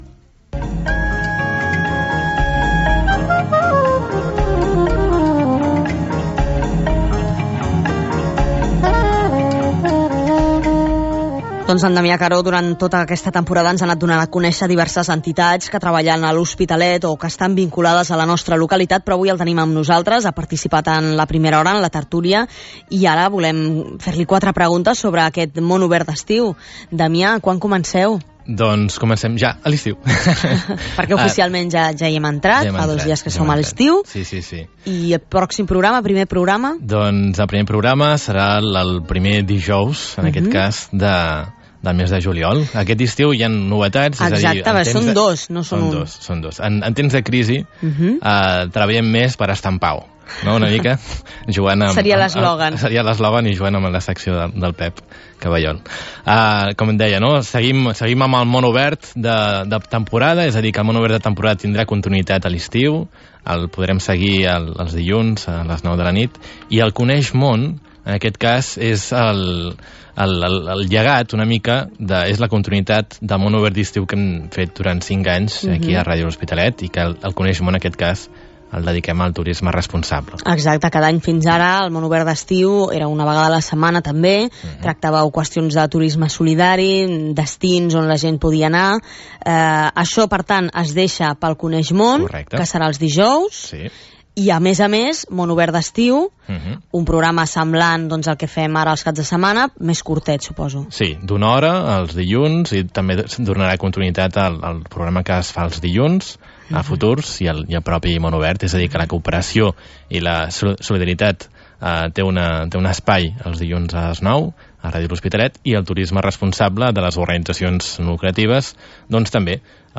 Noticias :: Entrevistas :: El "MÓN OBERT" de RADIO L'HOSPITALET con el PROGRAMA "CONEIX MÓN" DEDICADO al TURISMO RESPONSABLE